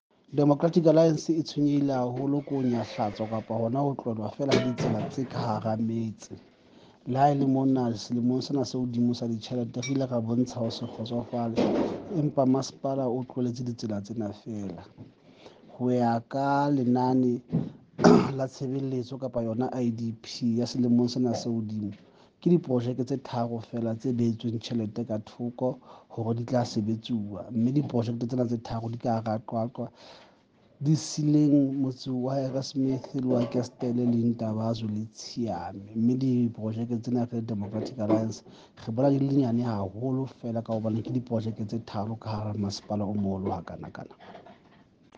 Sesotho by Cllr Moshe Lefuma and